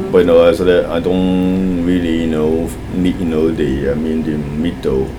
S1 = Taiwanese female S2 = Hong Kong male Context: They are talking about S2's name, particularly why he just uses a single syllable name, rather than the usual three-syllable Chinese name. S2 : you know as there i don:’t really you know f- need you know the i mean the middle Intended Words : need Heard as : meet Discussion : There is a glottal stop at the end of need , and S1 hears this as [t] rather than [d], partly because the preceding vowel is quite short (as would be expected before a voiceless final consonant). It is hard to detect whether the initial consonant is [n] or [m], so it is not surprising if S1 hears it as [m] instead of [n], even though meet makes no sense in this context.